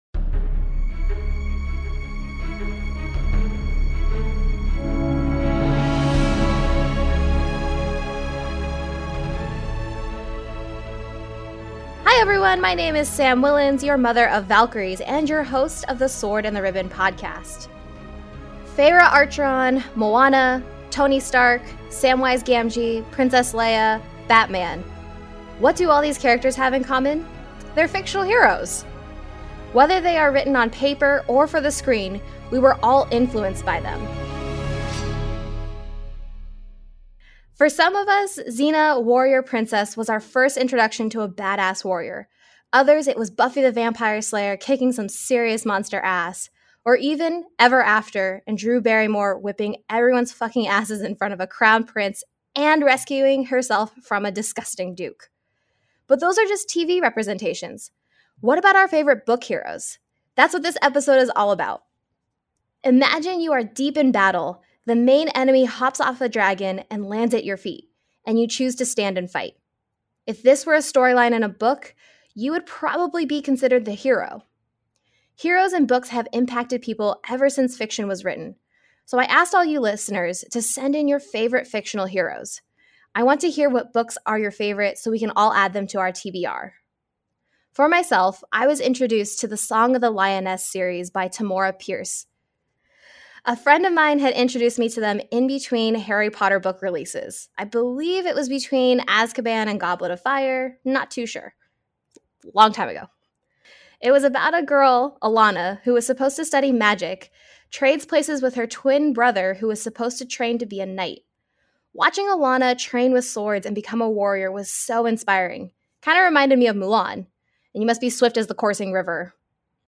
This episode is all about badass fictional heroes who inspire us. Listeners and I share our favorite stories and characters.